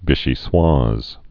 (vĭshē-swäz, vēshē-)